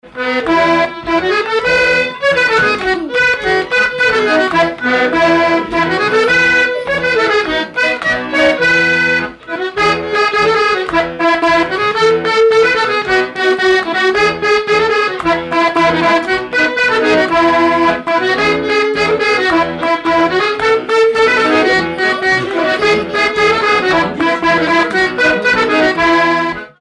Mémoires et Patrimoines vivants - RaddO est une base de données d'archives iconographiques et sonores.
Danse
Pièce musicale inédite